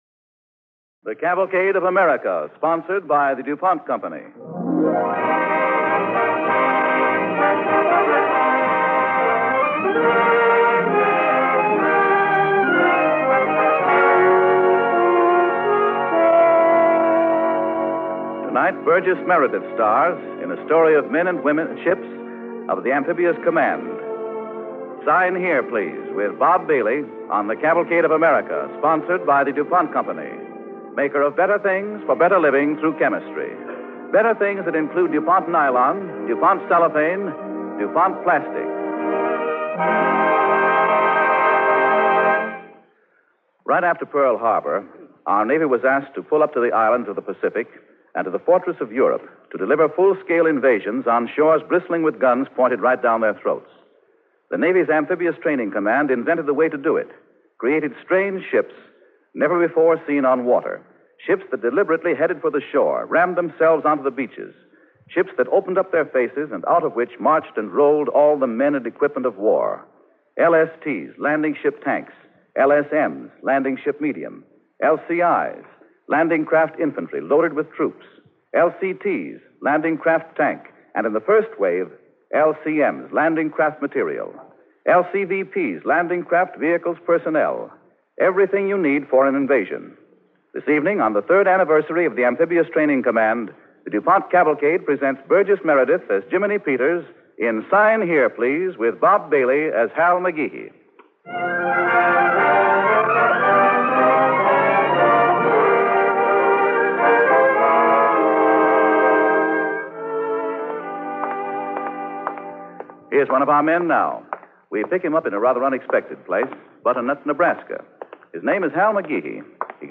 starring Burgess Meredith